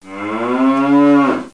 Kuh.mp3